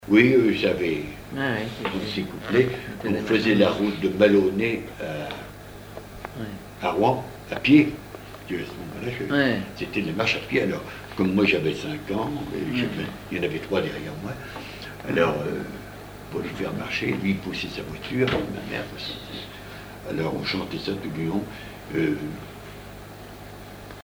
Chansons et commentaires
Témoignage